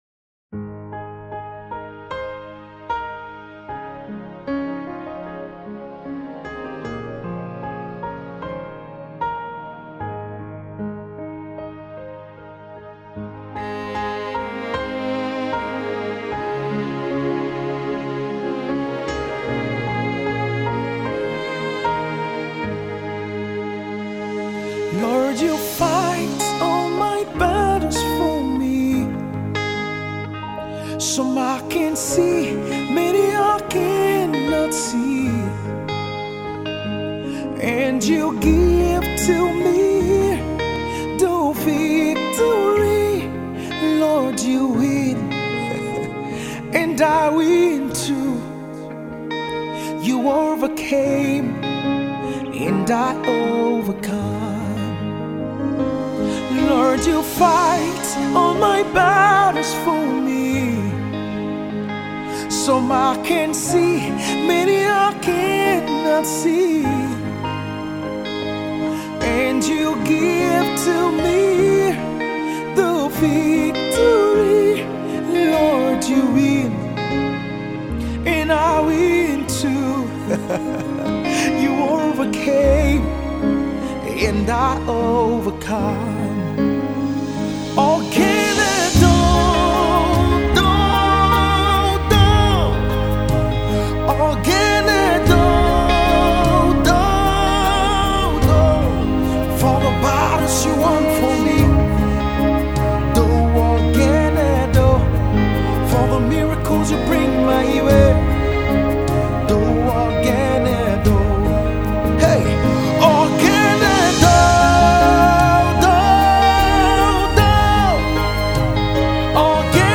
appreciation song